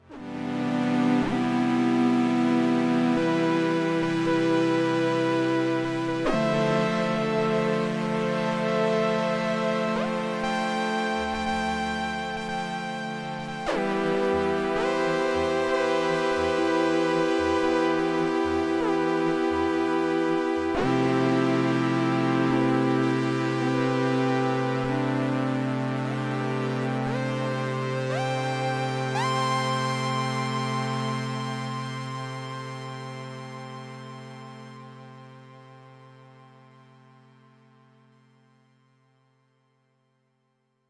Here it is with reverb and delay send at 12 'o clock.